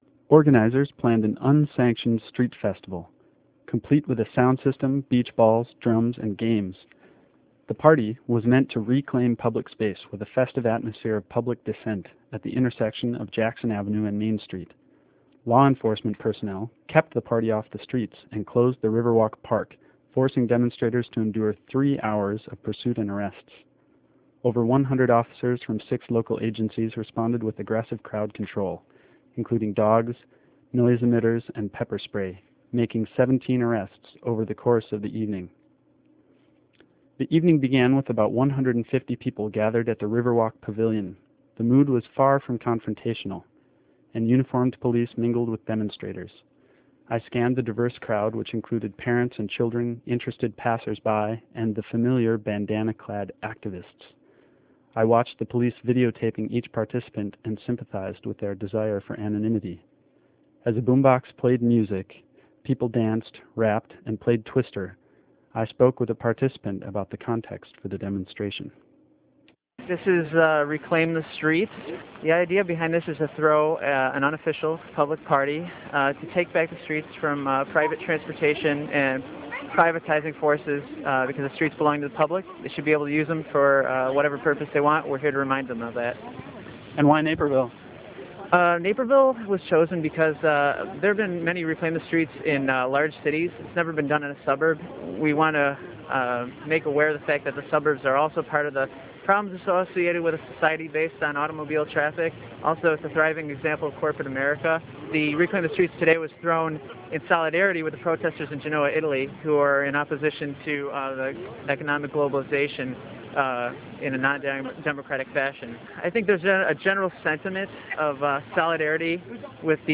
LAST FRIDAY EVENING JULY 20, A DEMONSTRATION IN THE CHICAGO SUBURB OF NAPERVILLE, PLANNED TO COINCIDE WITH THE G8 SUMMIT IN GENOA, WAS SQUELCHED BY POLICE REPRESSION AND VIOLENCE. W.O.R.T. REPORTER
REPORT